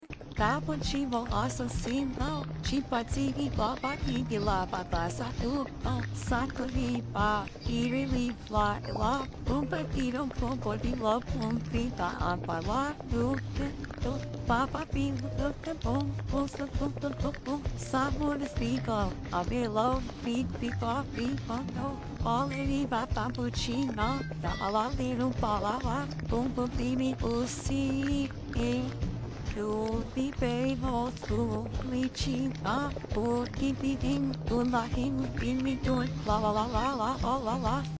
Cafe sound sound effects free download